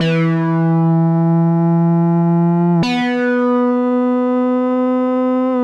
AM_CopMono_85-E.wav